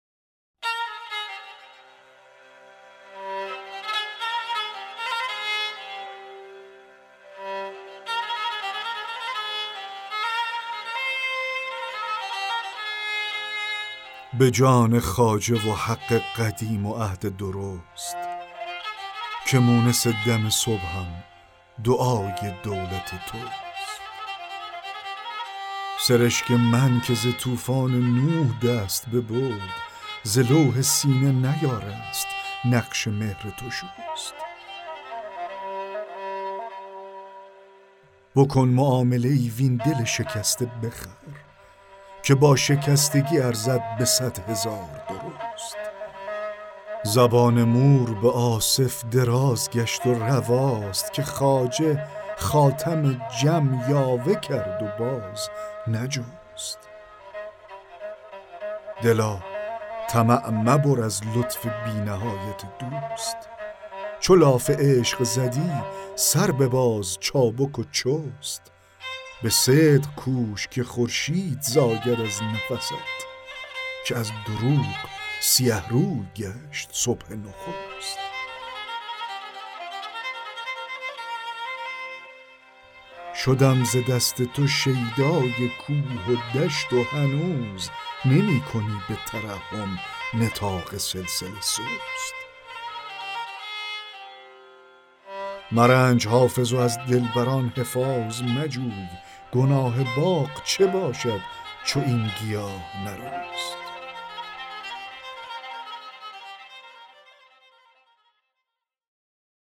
دکلمه غزل 28 حافظ
دکلمه-غزل-28-حافظ-به-جان-خواجه-و-حق-قدیم-و-عهد-درست.mp3